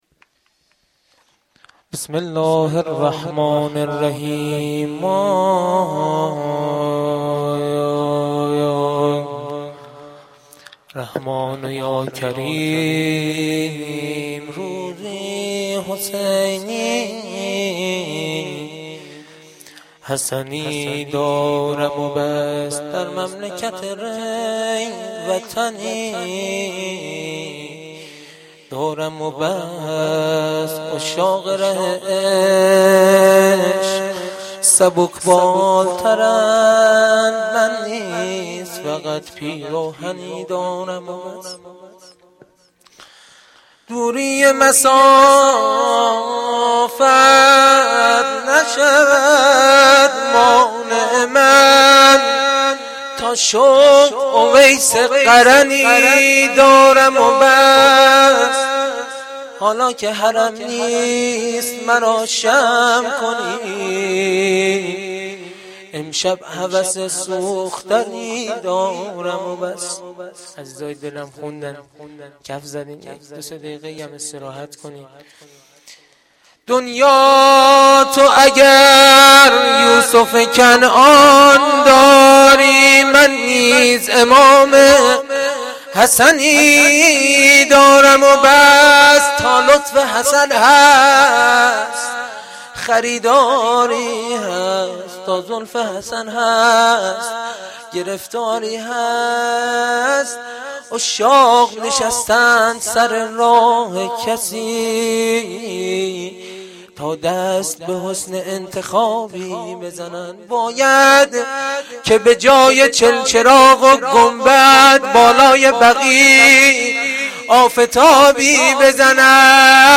شعرخوانی
sherkhani-Rozatol-Abbas.Milad-Emam-Hasan.mp3